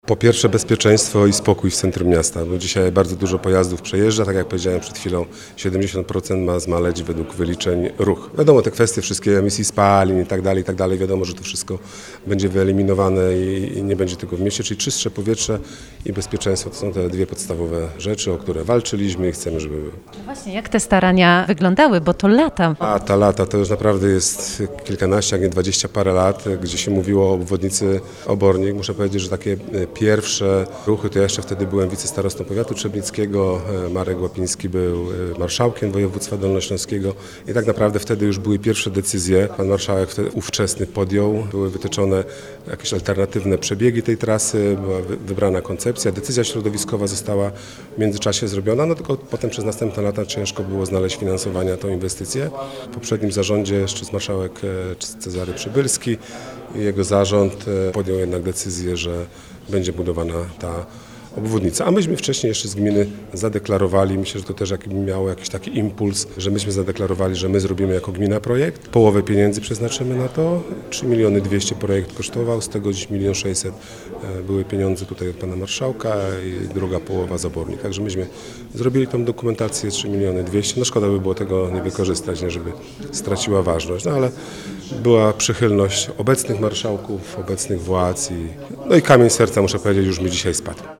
– Dzięki inwestycji ruch w mieście ma się zmniejszyć się o około 70%. Wzdłuż trasy posadzonych będzie kilkaset nowych drzew – wylicza Arkadiusz Poprawa, burmistrz Obornik Śląskich.